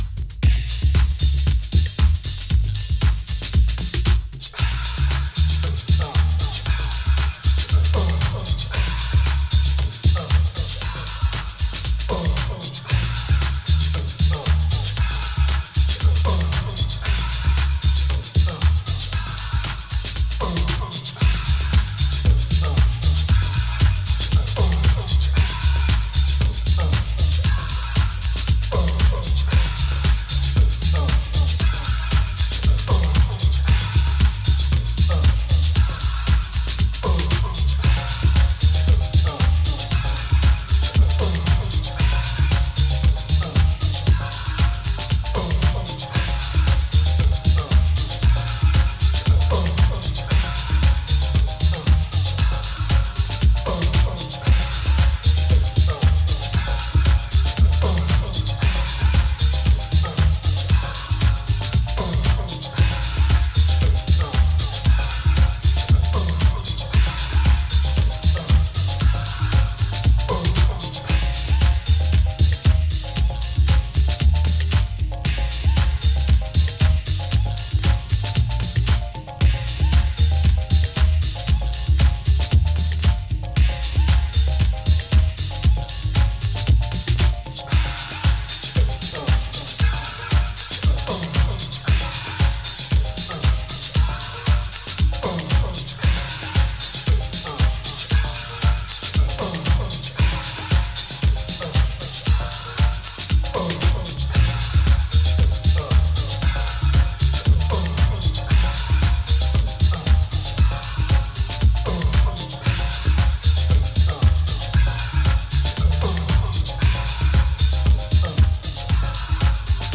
early Soulful Chicago house sounds
Deep house